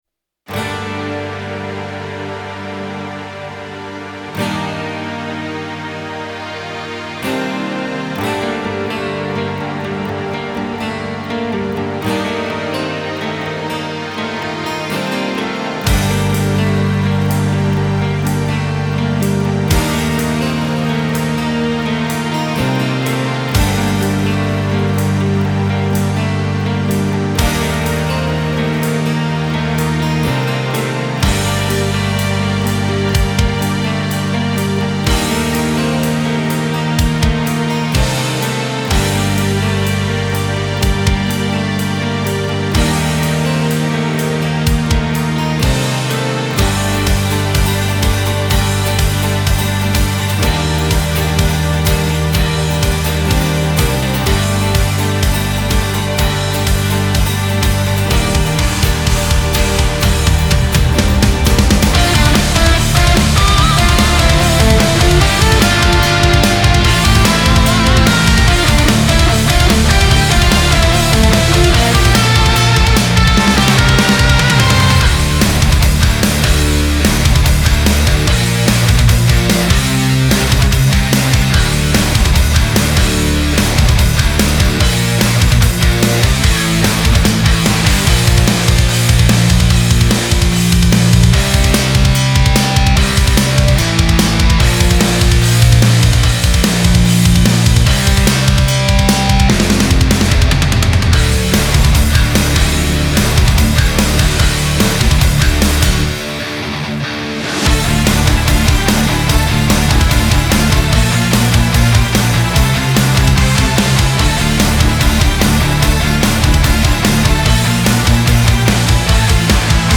(Инструментал)